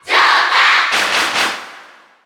Category: Crowd cheers (SSBU) You cannot overwrite this file.
Joker_Cheer_Japanese_SSBU.ogg.mp3